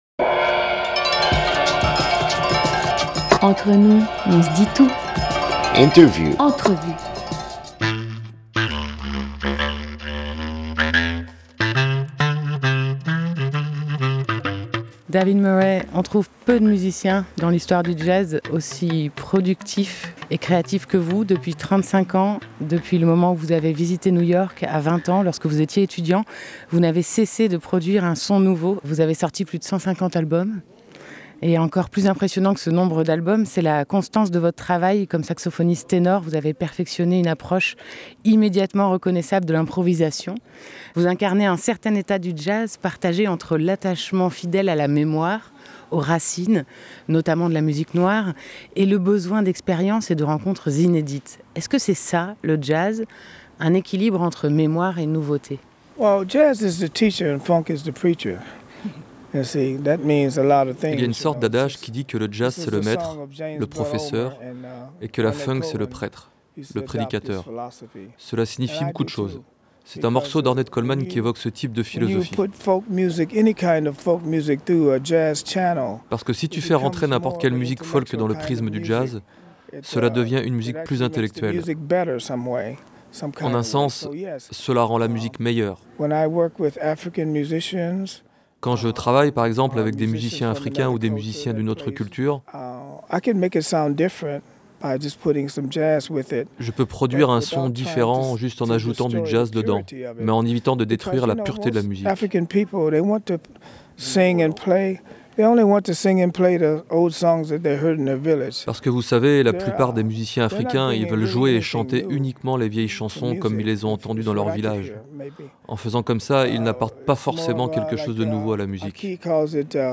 Plutôt que d'écouter Donald Trump, nous devrions réapprendre à écouter les poètes visionnaires....David Murray, le boss du saxophone ténor et de la clarinette basse, nous livre sa vision d'une musique qui élève et apaise l'esprit. Rencontre en 2016, lors du Poët-Laval Jazz Festival dont il a fait l'ouverture avec Aki Takase, suite à l'enregistrement en duo d'un nouvel album autour de Thélonious Monk, en Suisse.
24 juillet 2016 19:13 | Interview